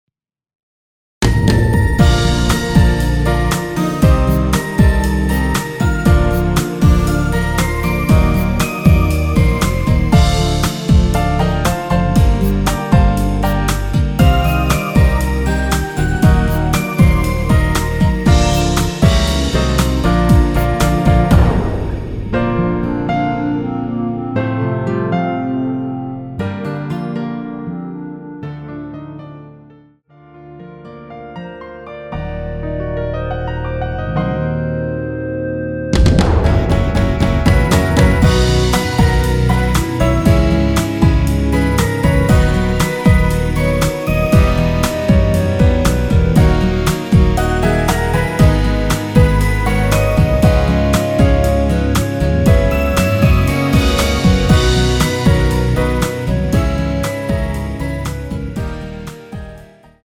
원키에서(-5)내린 멜로디 포함된 MR입니다.
Bb
멜로디 MR이란
멜로디 MR이라고 합니다.
앞부분30초, 뒷부분30초씩 편집해서 올려 드리고 있습니다.